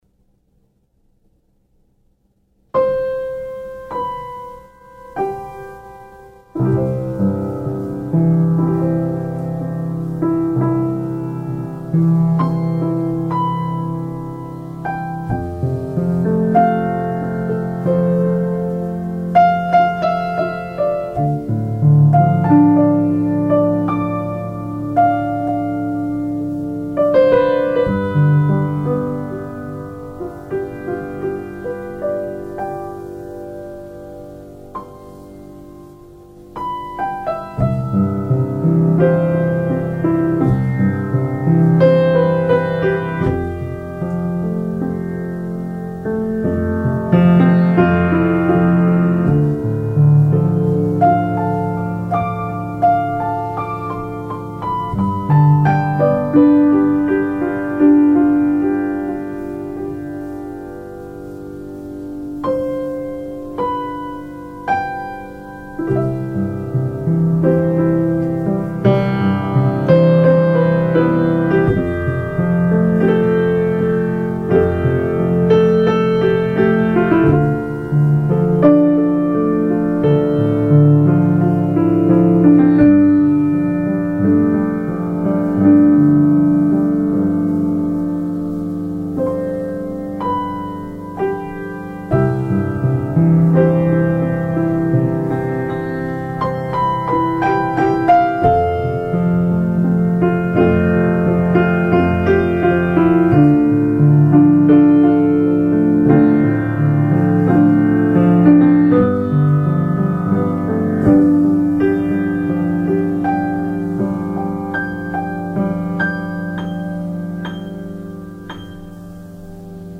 Music Gallery of my Grand Piano Music